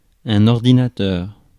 Ääntäminen
IPA : /ˈhaɪ.dʒæk/